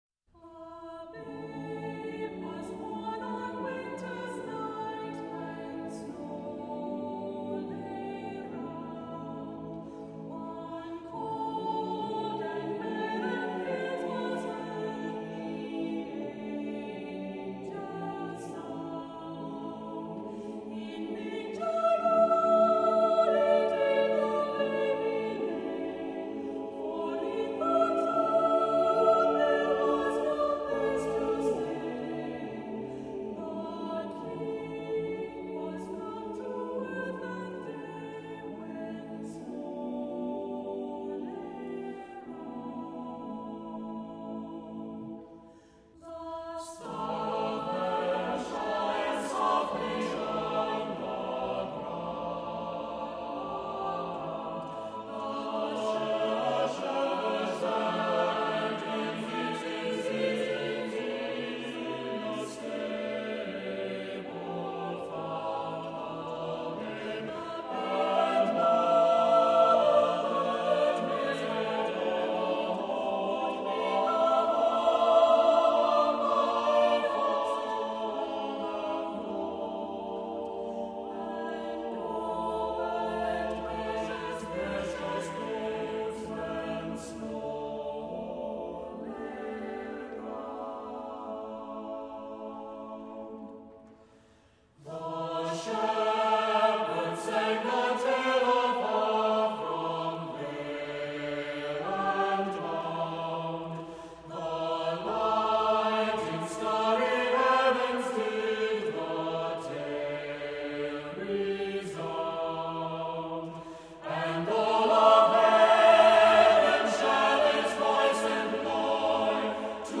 Accompaniment:      Reduction
Music Category:      Choral